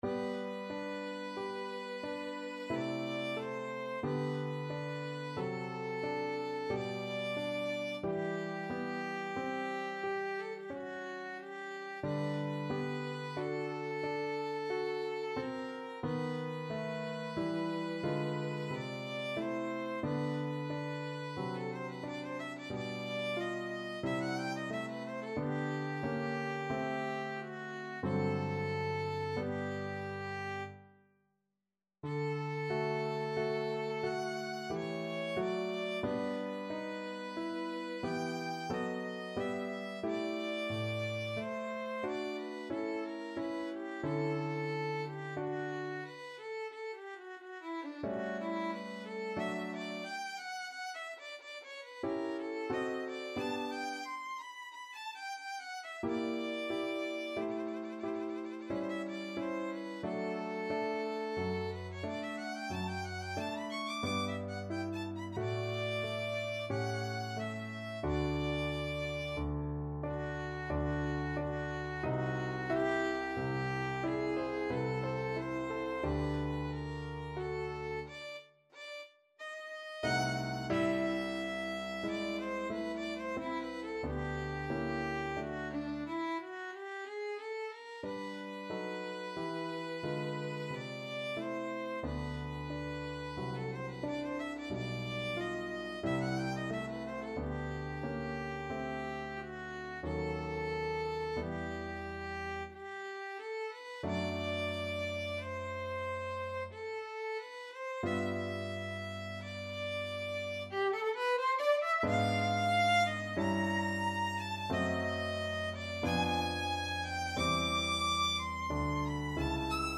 Classical Spohr, Louis Clarinet Concerto No.1, Op.26, Second Movement Violin version
G major (Sounding Pitch) (View more G major Music for Violin )
3/4 (View more 3/4 Music)
Adagio =45
Classical (View more Classical Violin Music)